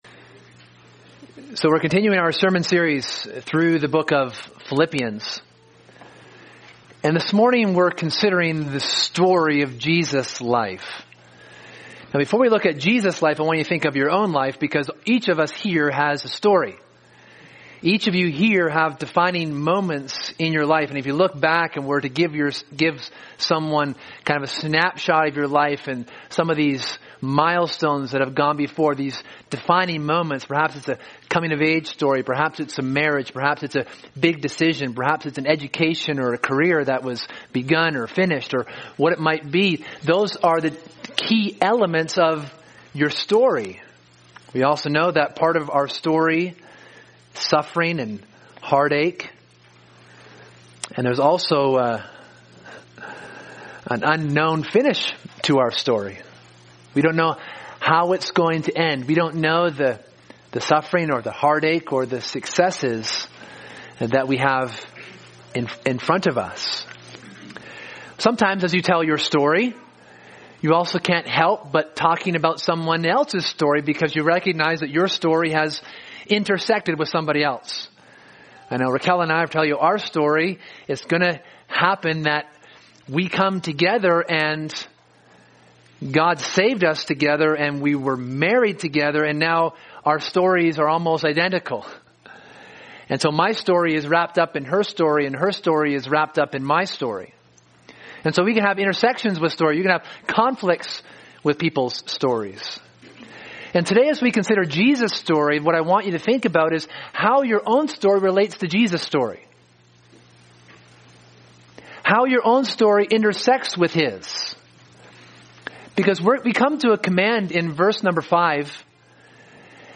Sermon: From Heaven to Earth and Back Again: The Story of Jesus
( Sunday AM )